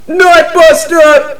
mvm_sentry_buster_alerts01.mp3